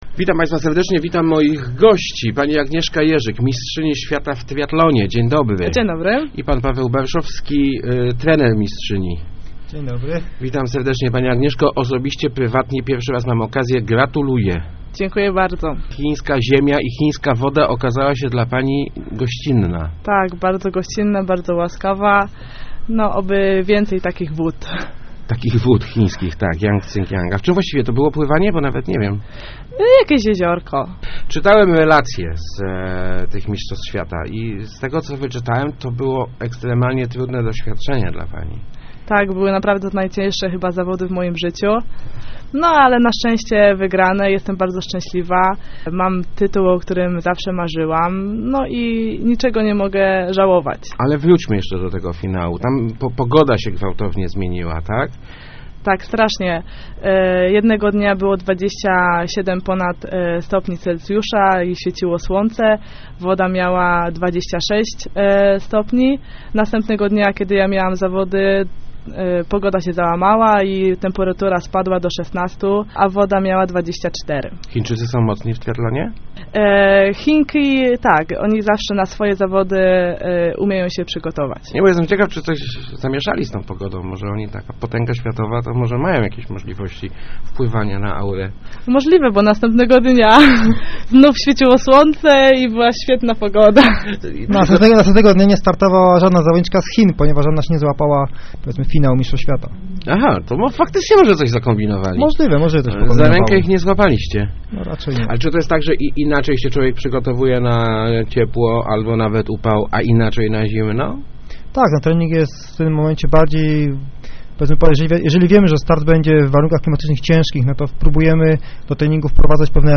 Po ostatnich startach jestem na 40 miejscu w klasyfikacji światowej - mówiła w Rozmowach Elki Agnieszka Jerzyk, Mistrzyni Świata w triathlonie. To teoretycznie wystarcza do kwalifikacji olimpijskiej, jednak start w Londynie wciąż nie jest pewny.